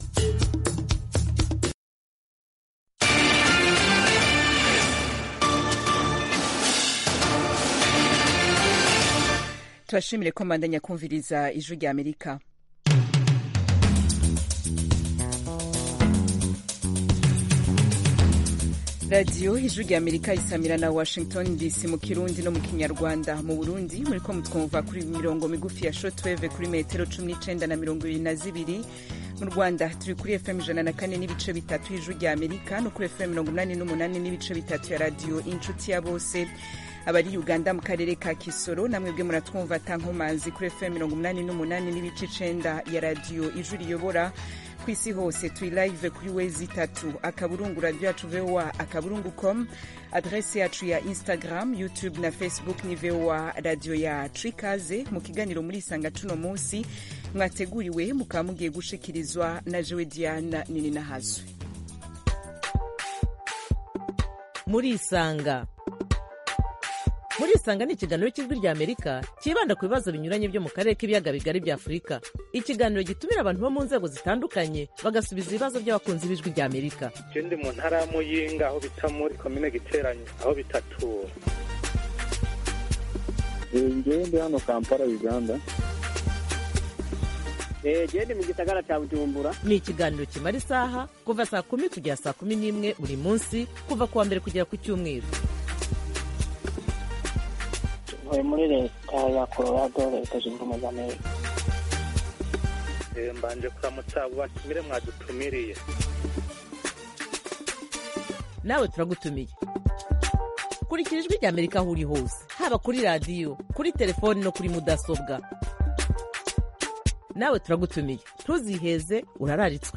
Mu Burundi ishirahamwe Focode ribicishije mw'isekeza ryayo, Ndondeza, ryemeza ko abo rimaze kumenya ko baburiwe irengero kuva mu 2015 barenga 200. Mu kiganiro umumenyeshamakuru w'Ijwi ry'Amerika